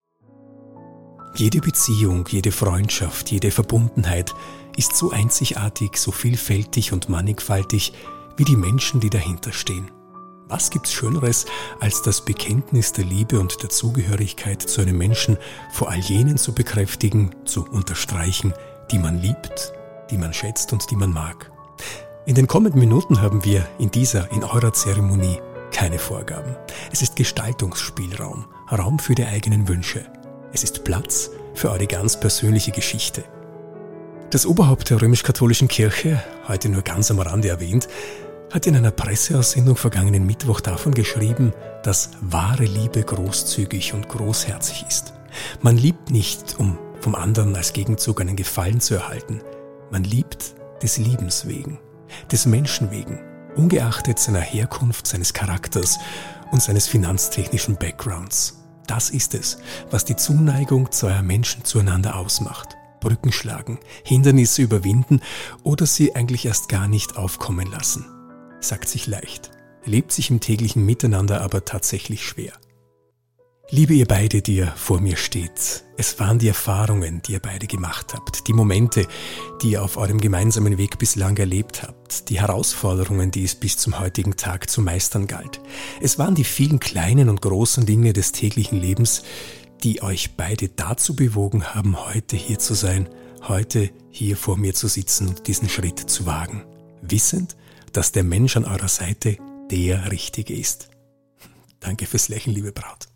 meine stimme
Traurede-.mp3